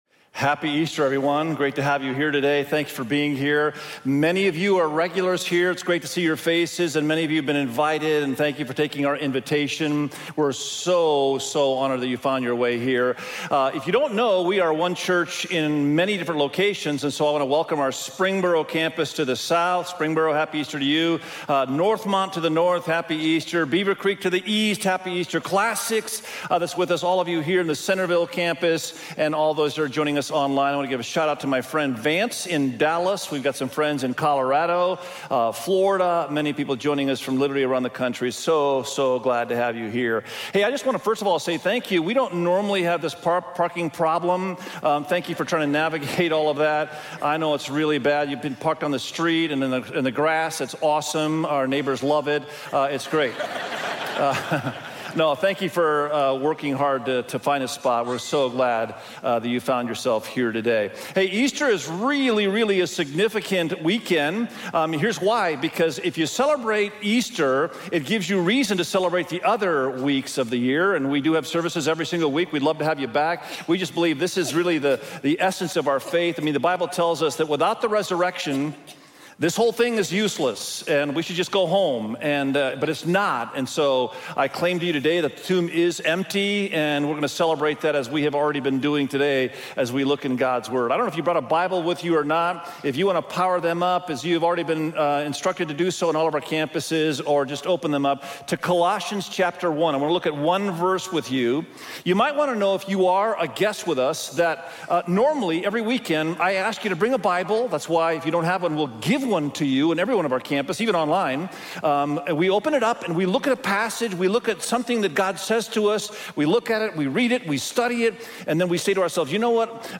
Easter_SERMON.mp3